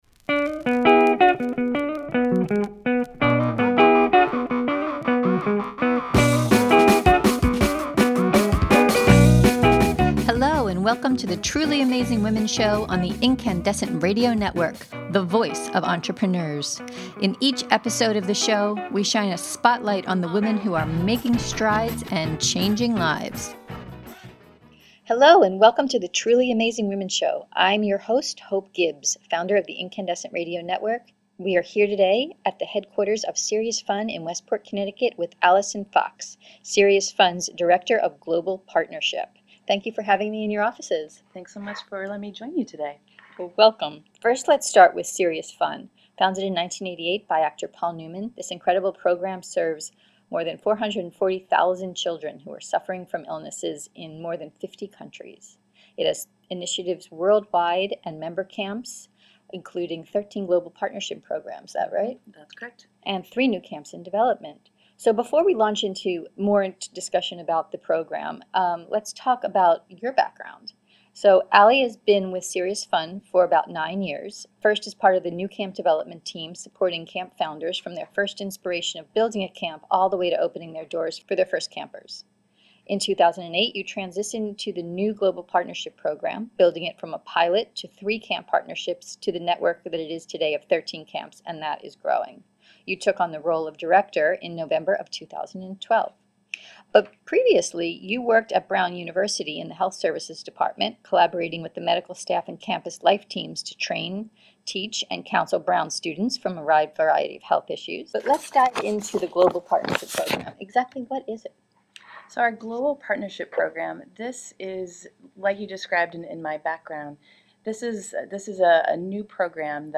We are here today at the hq of SeriousFun in Westport, CT
In this podcast interview